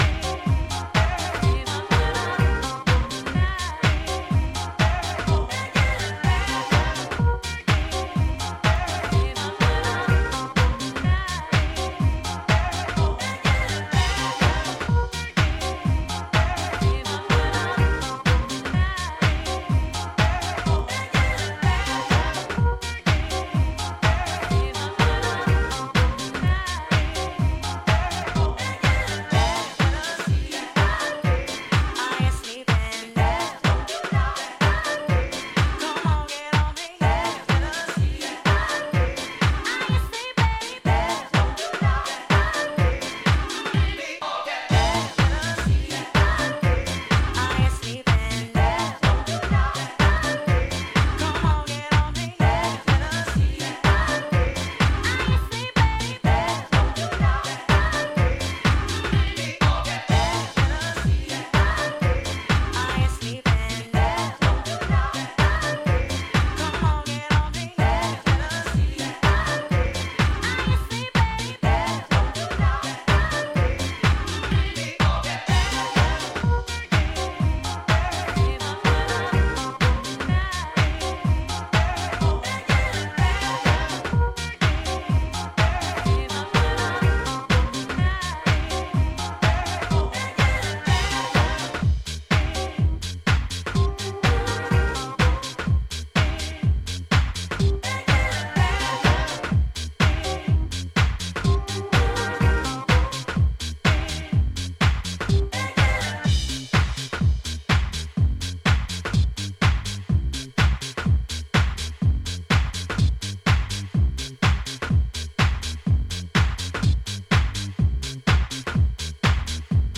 ジャンル(スタイル) HOUSE / DEEP HOUSE / CLASSIC